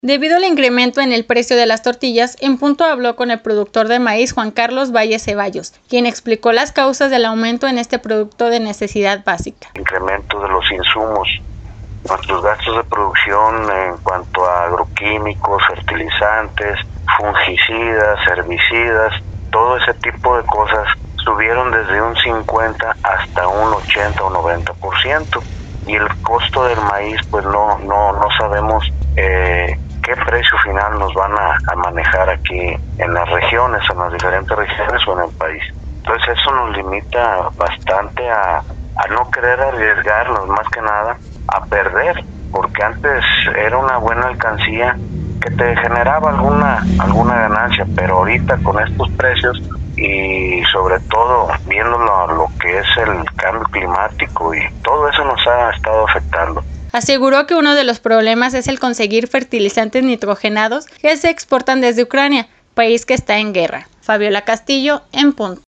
Productor de maíz nos habla del porqué el aumento en el precio del producto